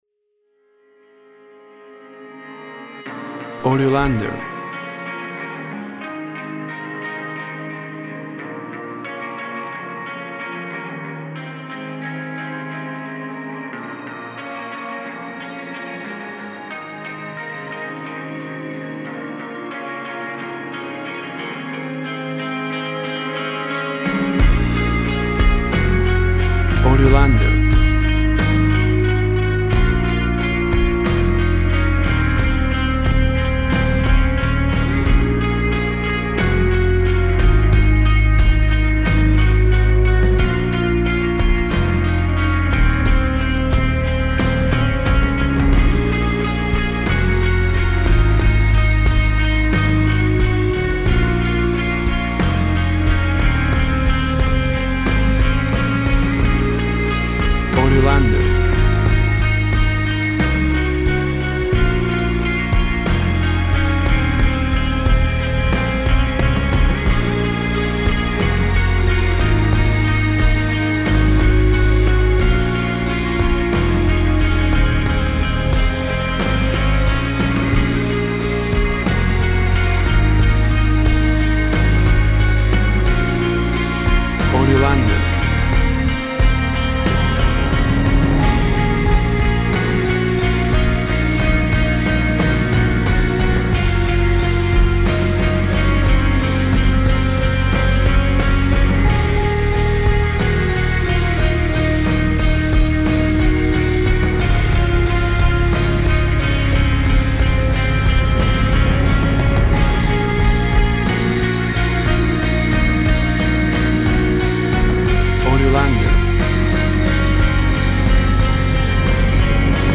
Post Rock/Orchestral track
WAV Sample Rate 24-Bit Stereo, 44.1 kHz
Tempo (BPM) 90